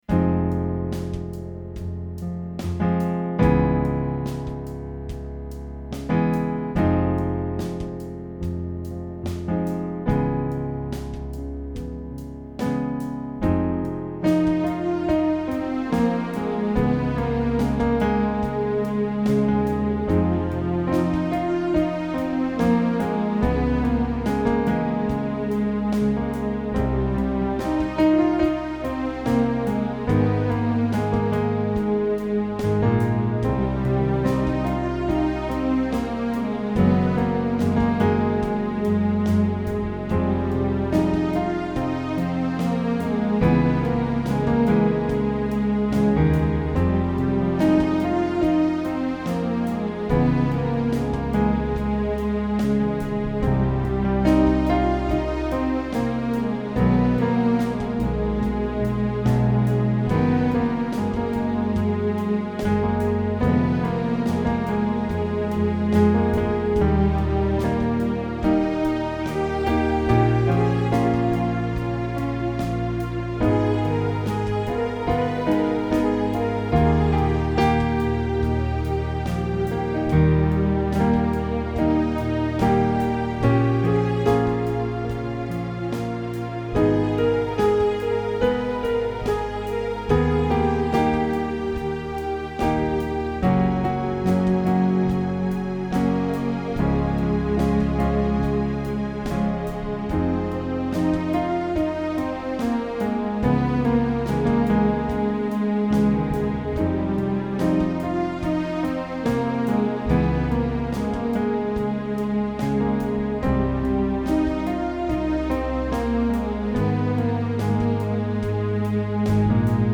Also following my formula of "repeat the melody line over and over with different chords".